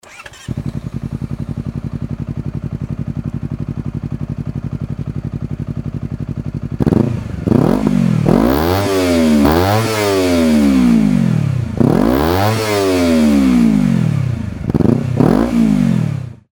排気音サンプル（ビームスマフラーバッフル無し：空ふかし）
明らかに低音が強くなっていて
アイドリング時の音圧は2.5dBほどアップしましたが
想像より遙かに静かというか爆音にはなりませんでしたが
cbr250rr-bmsr-bfl.mp3